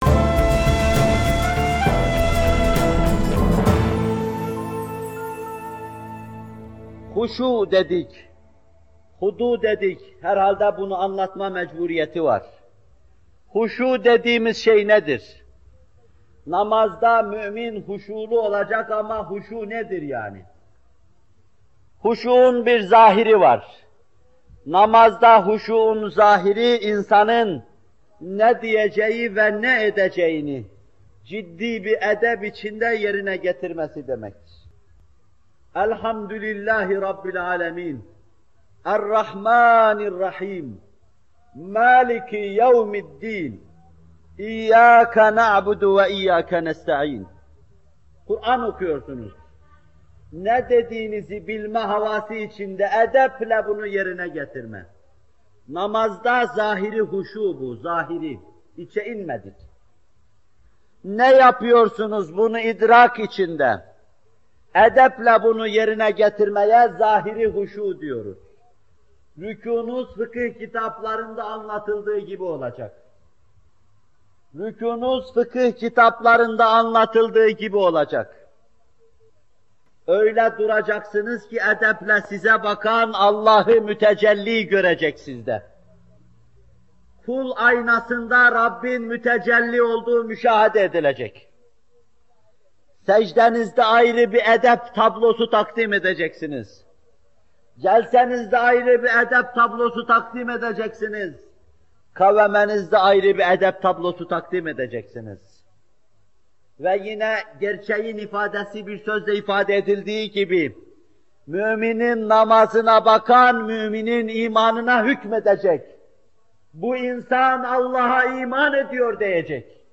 Bu bölüm Muhterem Fethullah Gülen Hocaefendi’nin 15 Eylül 1978 tarihinde Bornova/İZMİR’de vermiş olduğu “Namaz Vaazları 5” isimli vaazından alınmıştır.